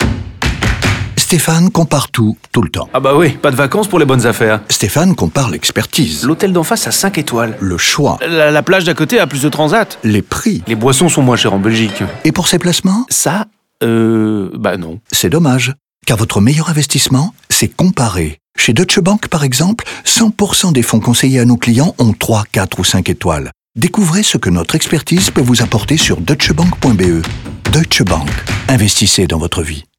N’oublions pas la radio, mettant en scène Stéphane, un homme qui compare tout, tout le temps, sauf pour ses investissements, bien évidemment.
DeutscheBank-LeComparateur-Radio-FR-30s-Vacances-051217.mp3